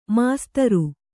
♪ māstaru